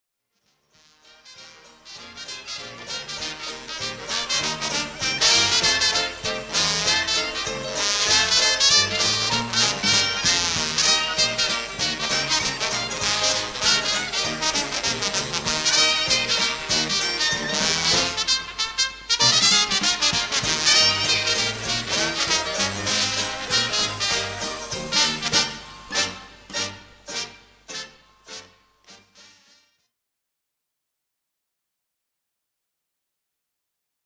begeistert mit Dixieland und traditionellem Jazz
Trompete, Fl�gelhorn
Posaune
Klarinette
Saxophon
Banjo, Gitarre
Schlagzeug, Gesang
Sousaphon